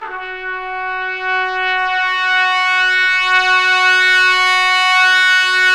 Index of /90_sSampleCDs/Roland LCDP06 Brass Sections/BRS_Stabs-Swells/BRS_Tps Swells